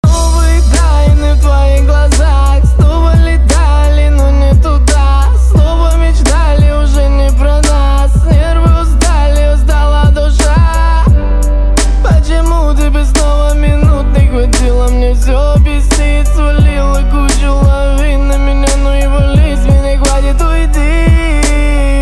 • Качество: 320, Stereo
печальные